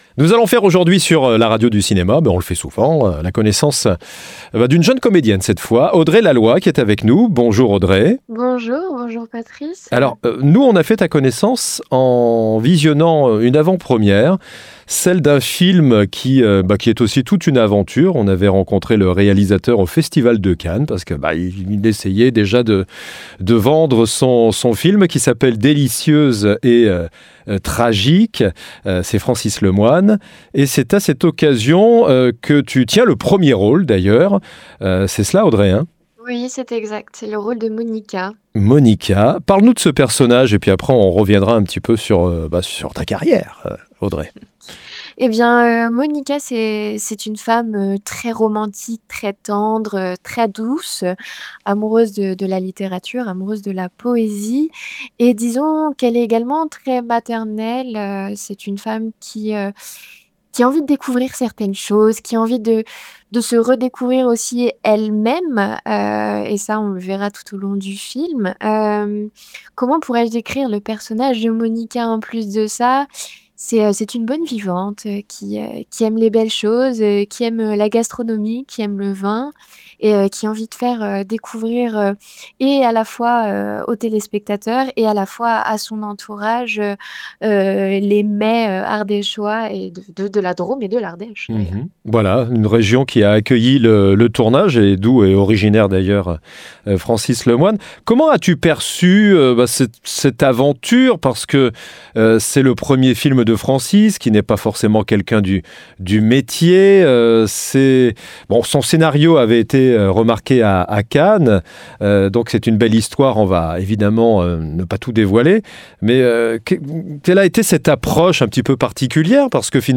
Un projet atypique tourné entre Drôme et Ardèche, et présenté en avant-première sur les lieux du tournage. Rencontre avec une comédienne solaire, littéraire et gourmande.